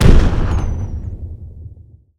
Explosion.aif
explosion.aif